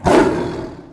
CosmicRageSounds / ogg / general / combat / creatures / tiger / he / hurt1.ogg
hurt1.ogg